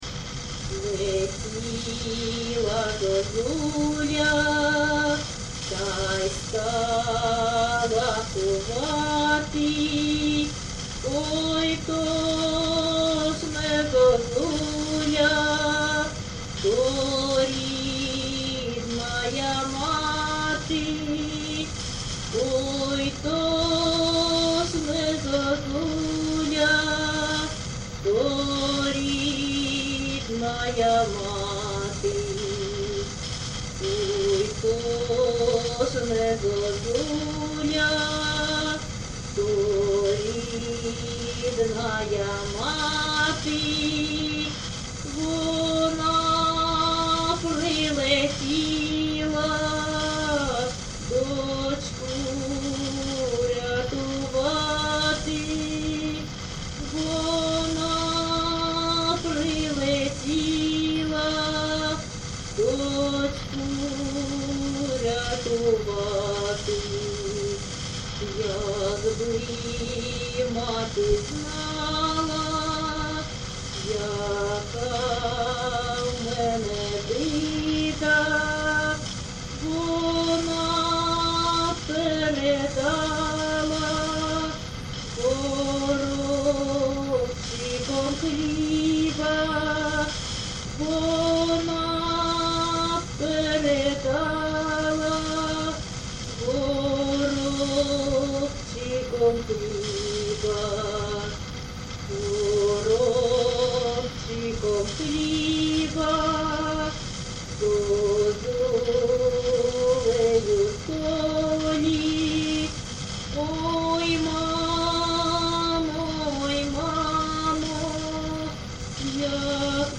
ЖанрПісні з особистого та родинного життя
Місце записус. Рідкодуб, Краснолиманський (Лиманський) район, Донецька обл., Україна, Слобожанщина